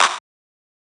TM88 - CLAP (9).wav